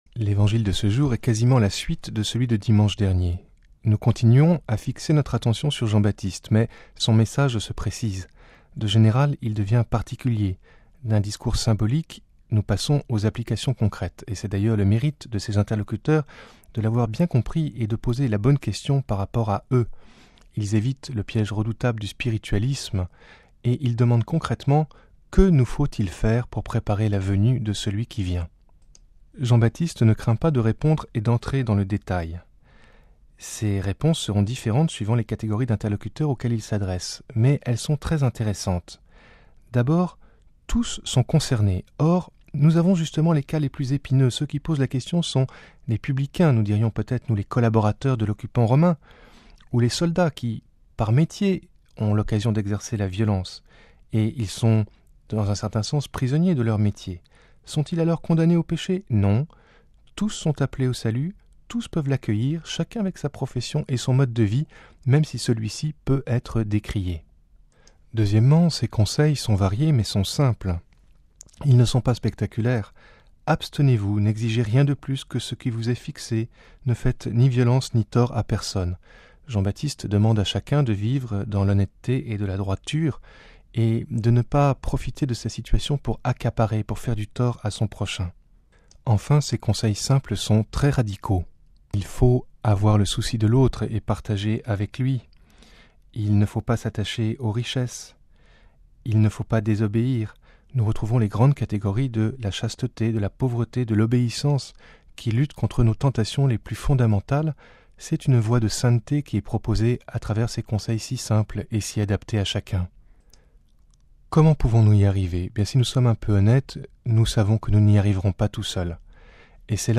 Le commentaire de l'Evangile